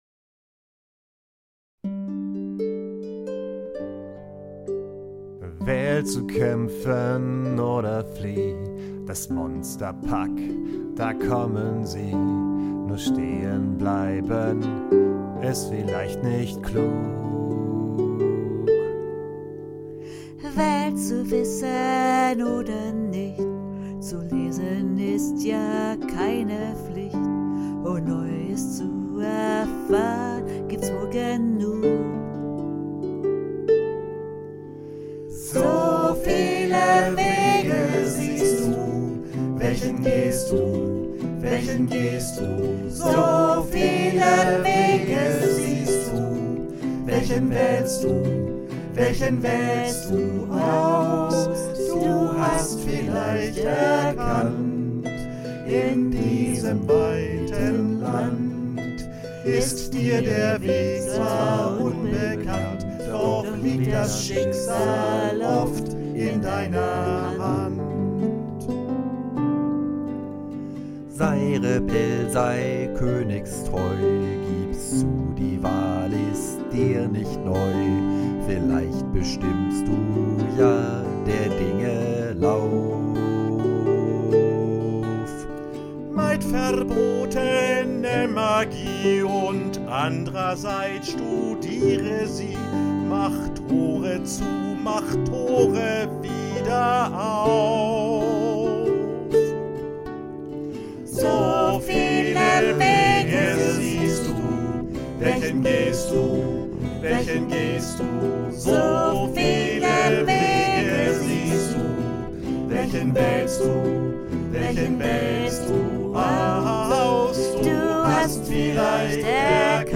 Tenor
Bariton